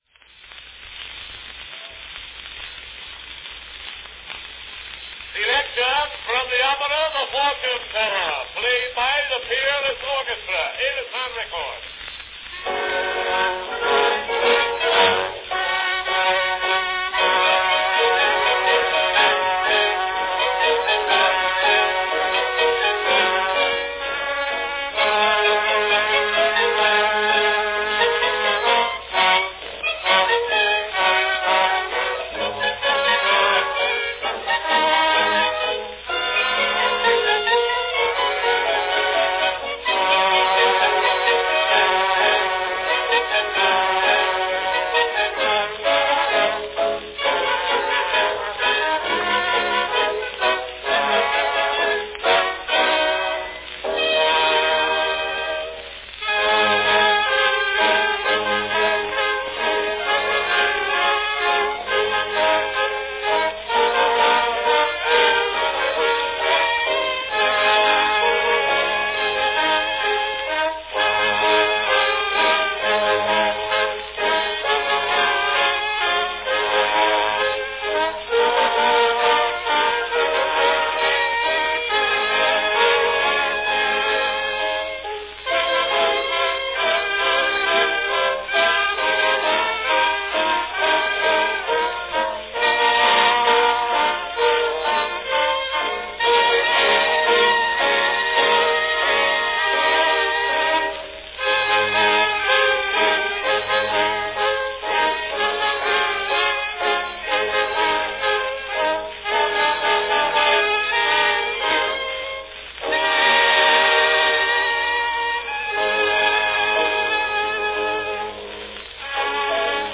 From 1899, the Peerless Orchestra performs Selections from The Fortune Teller featuring the famous Gypsy Love Song.
Category Orchestra
Performed by Peerless Orchestra
Announcement "Selections from the opera The Fortune Teller, played by the Peerless Orchestra.  Edison record."
Although frequently revived and performed in a popular up-tempo fashion by such personalities as Bing Crosby, Connee Boswell, and Chico Marx ("The Cocoanuts", 1929), it was originally known, as you will hear, in its melancholy almost lachrymose rendering.
Original (Edison) National Phonograph Company record slip.